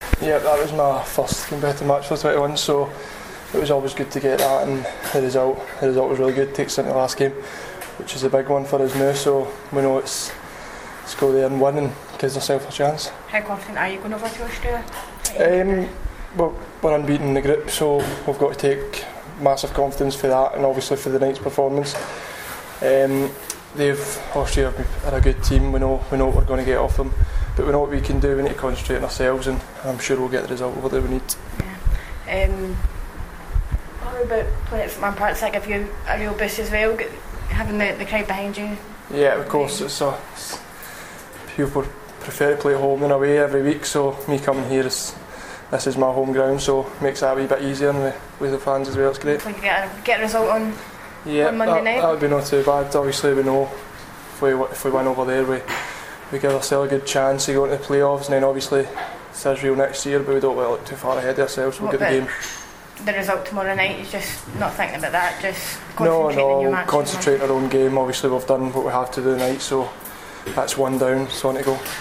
Kenny McLean speaks to The Away End after making his debut for the Scotland U21s.